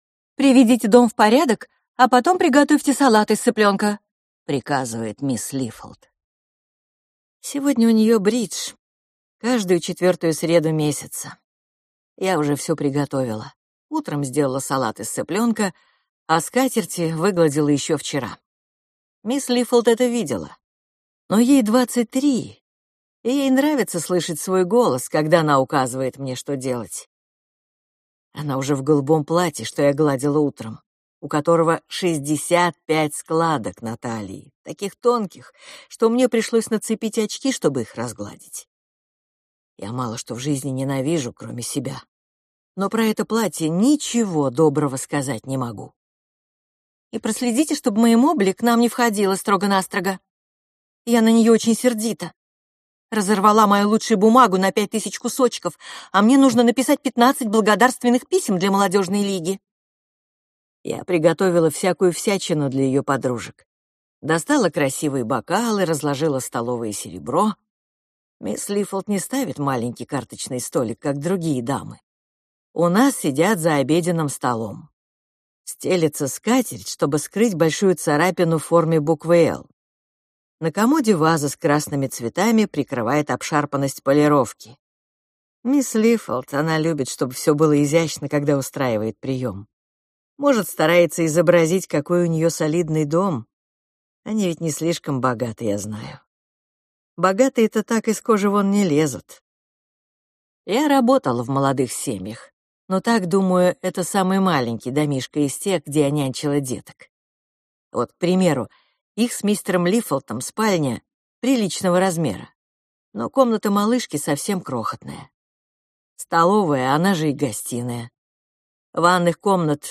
Аудиокнига Прислуга - купить, скачать и слушать онлайн | КнигоПоиск